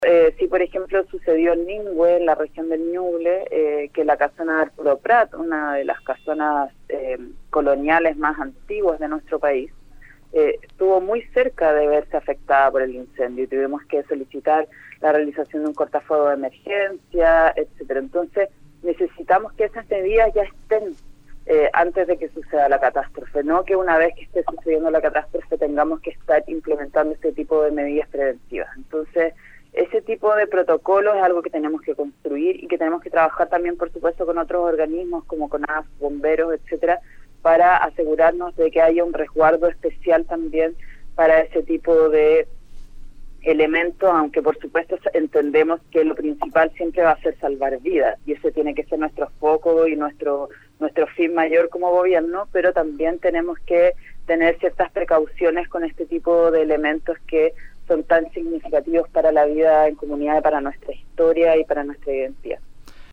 En entrevista con Radio UdeC, la ministra detalló los ejes del Plan de Apoyo a las Zonas Afectadas por Incendios Forestales, en el que se aborda la reconstrucción de la vida en comunidad, las culturas campesinas, las distintas formas de vida y el patrimonio de las zonas afectadas.